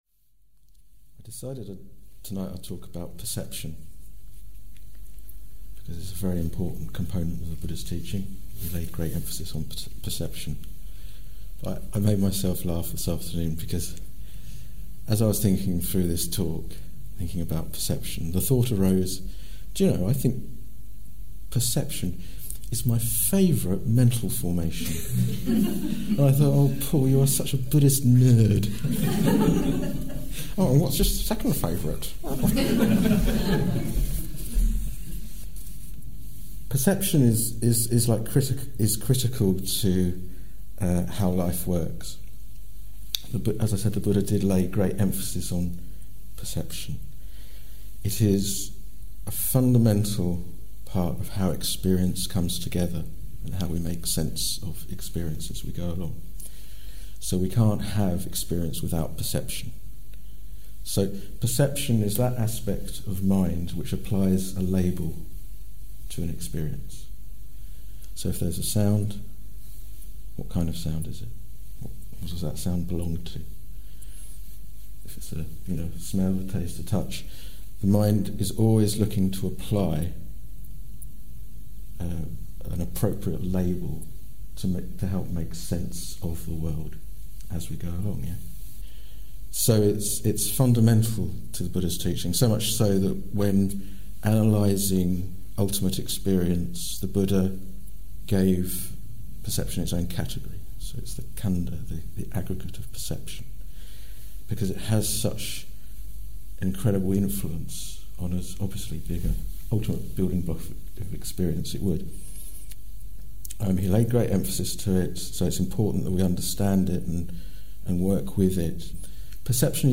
This talk was given in October 2016